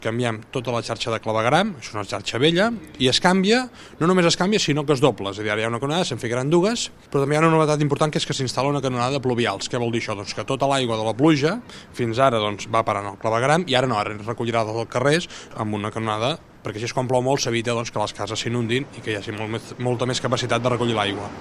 L’alcalde Marc Buch ha recordat en una entrevista a l’FM i + quina feina es farà sota terra: